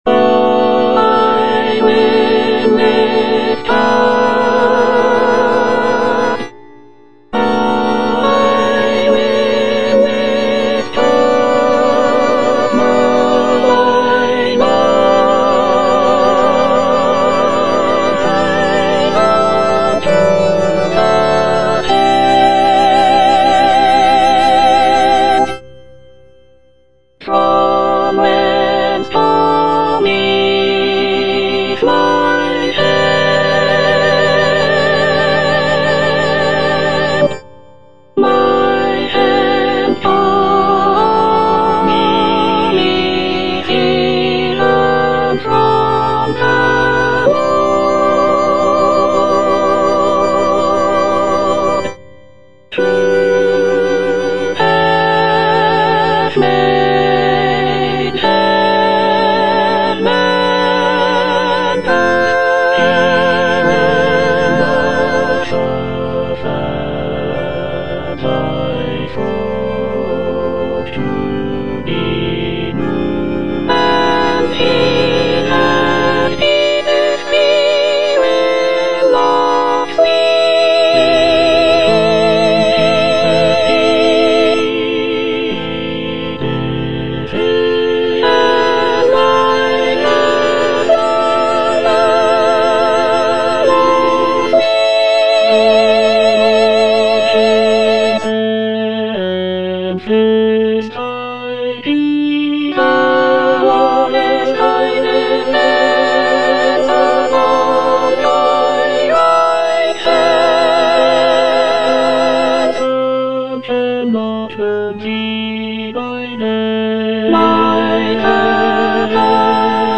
Soprano I (Emphasised voice and other voices)
choral work